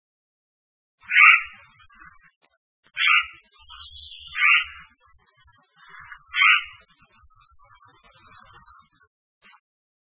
〔ホシガラス〕ガーッ，ガーッ／高山で繁殖し冬は漂行，少ない・留鳥，35p，雌雄同
hosigarasu.mp3